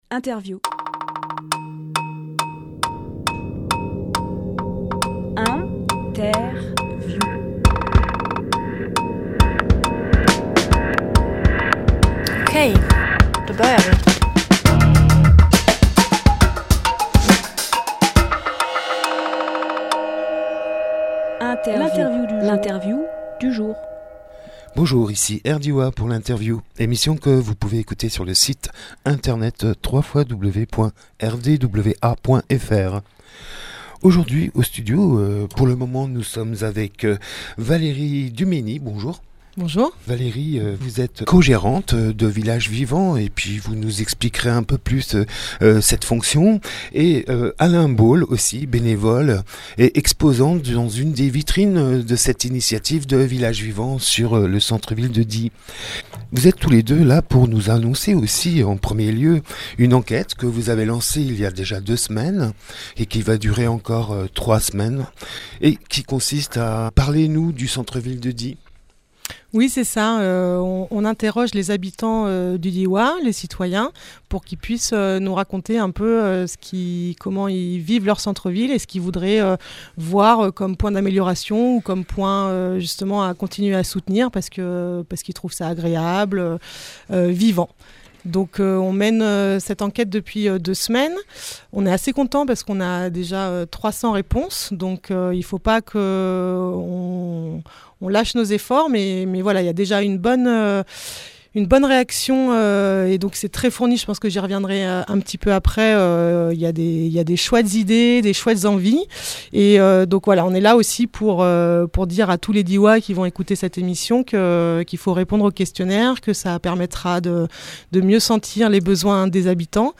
Emission - Interview Villages Vivants en quête de Die Publié le 3 octobre 2018 Partager sur…
Lieu : Studio RDWA